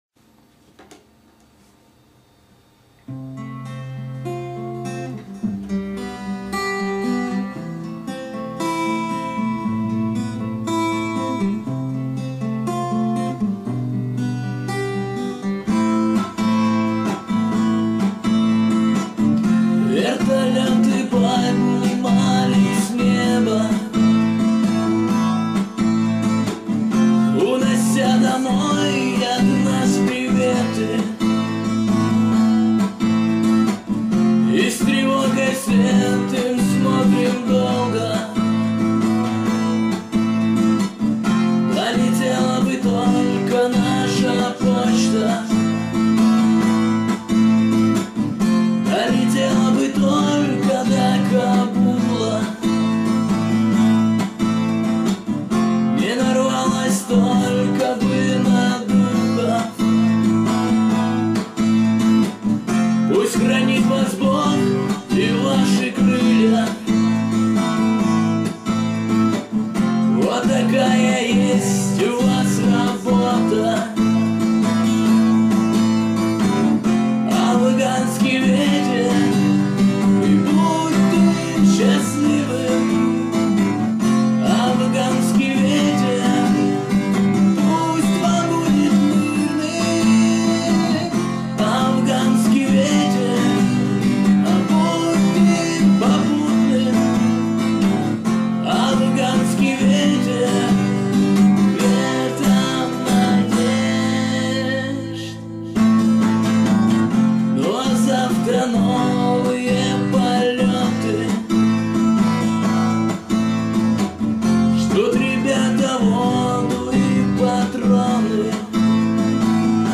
Главная » Файлы » Песни под гитару » Песни у костра
Песни у костра [44]